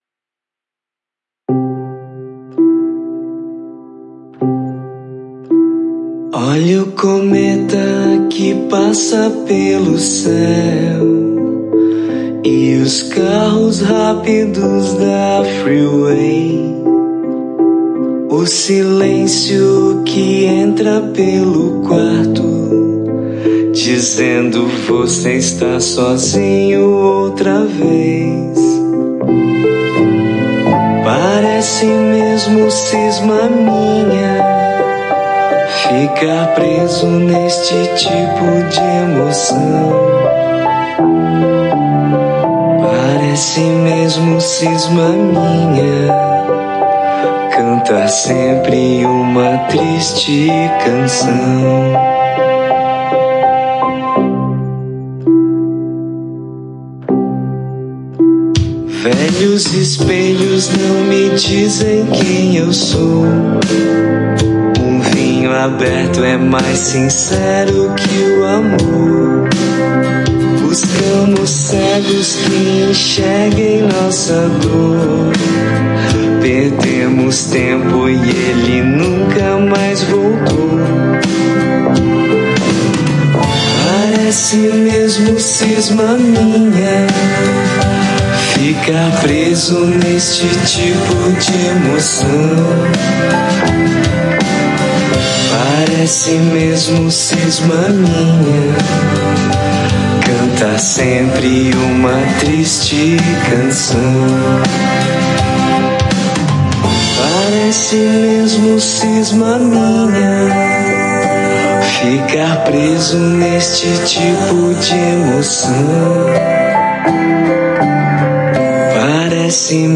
um novo single intimista